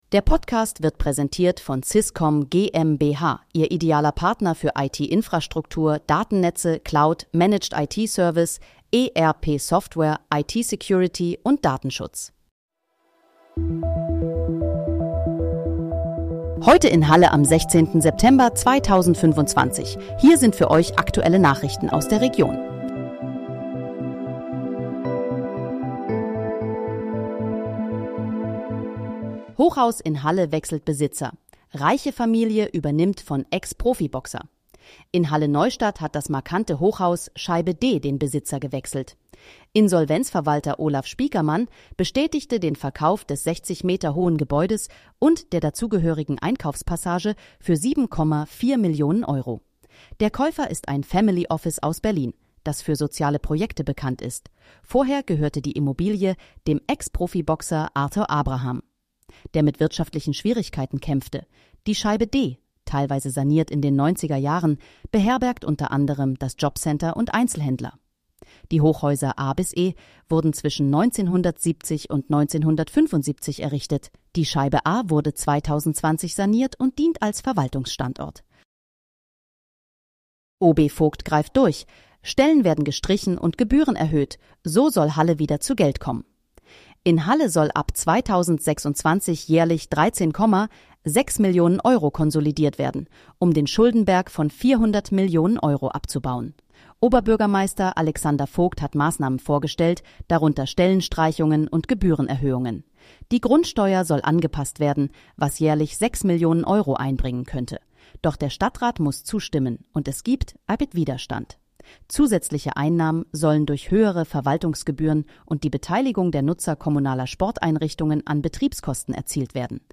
Heute in, Halle: Aktuelle Nachrichten vom 16.09.2025, erstellt mit KI-Unterstützung
Nachrichten